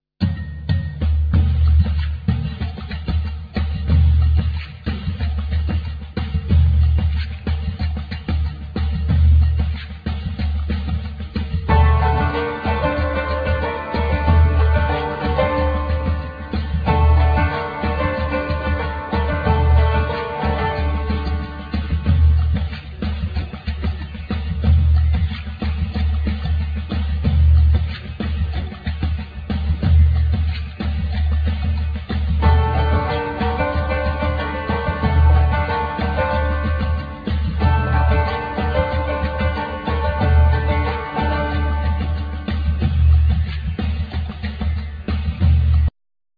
Cretan lyra
Duduk,Shevi
Nylon string guitar,Mandokino,Bass